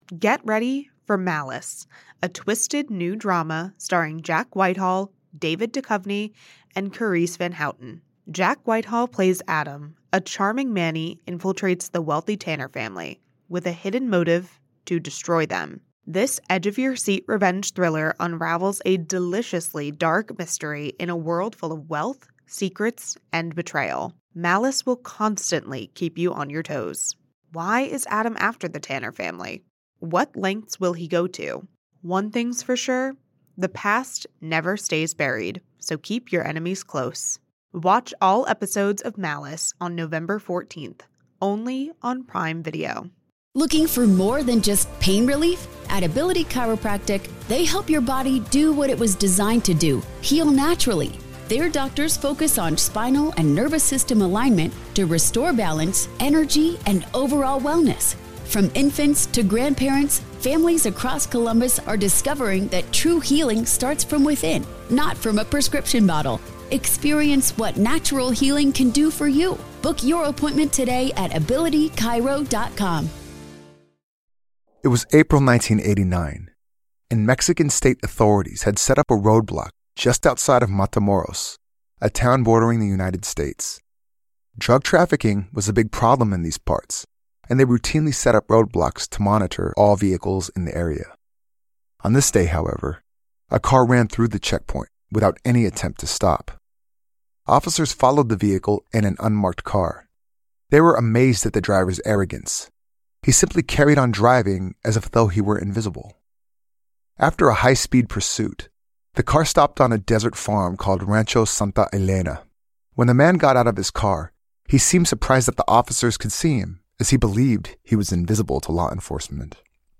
True Crime Podcast